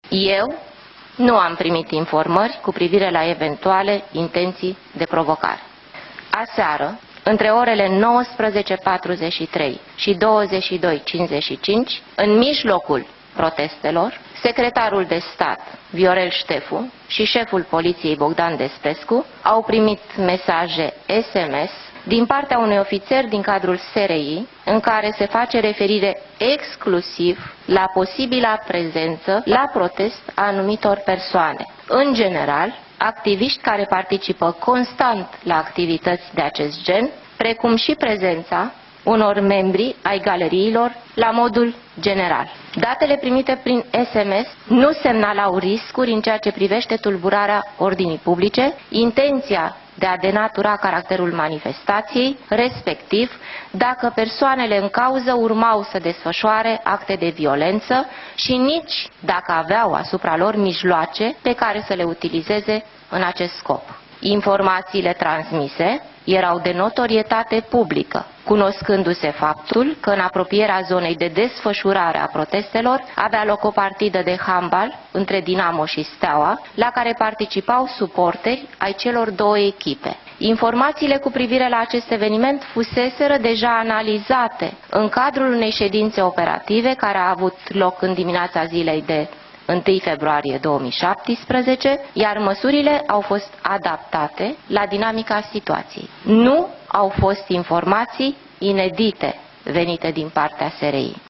Este precizarea făcută în urmă cu puțin timp de ministrul de interne, Carmen Dan.